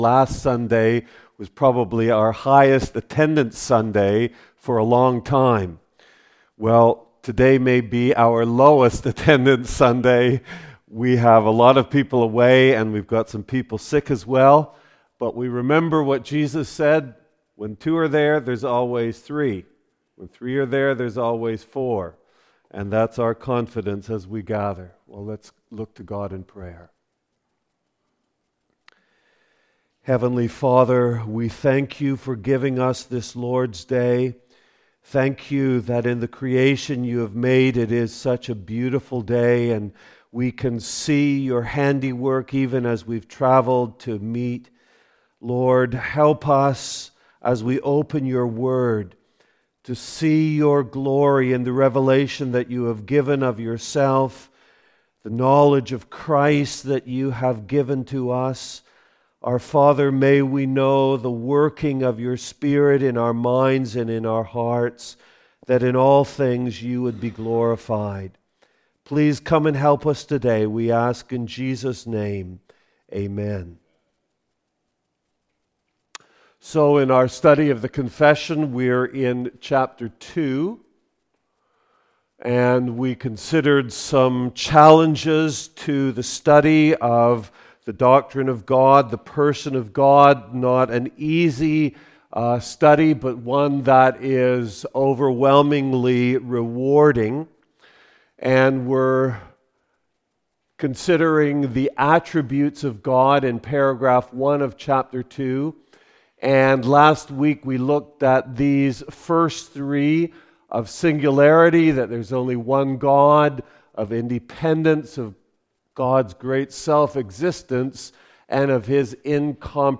What We Believe Service Type: Adult Sunday School « God and the Holy Trinity